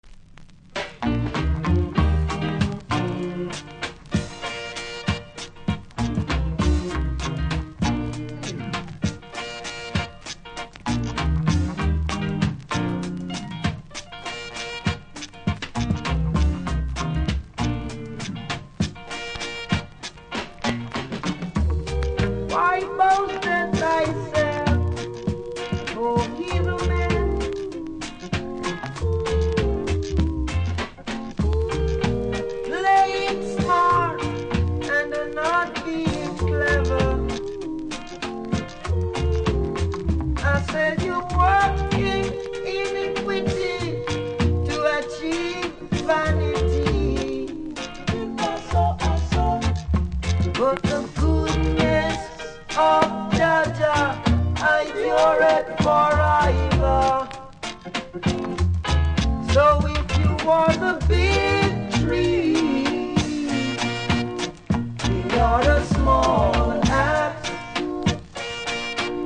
この盤は全てスタンパー起因のノイズがありますのでノイズ無しで探すのは諦めましょう。